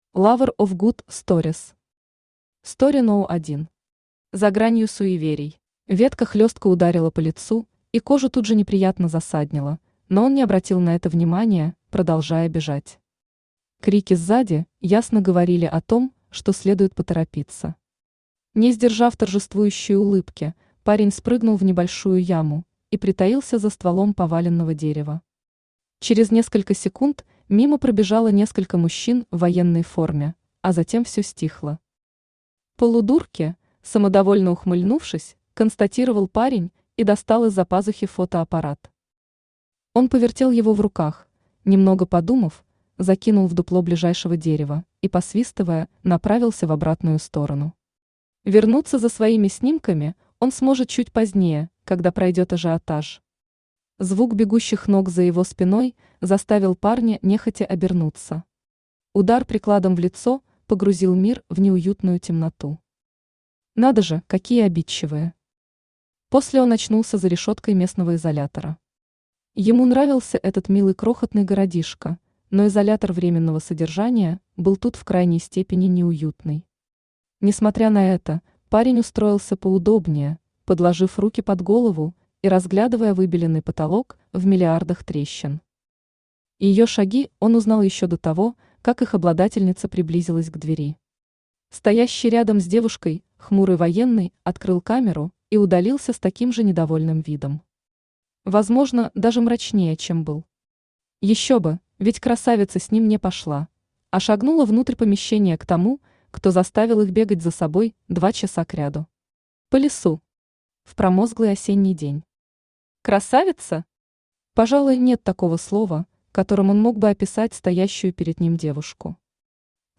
Аудиокнига STORY № 1. За гранью суеверий | Библиотека аудиокниг
За гранью суеверий Автор Lover of good stories Читает аудиокнигу Авточтец ЛитРес.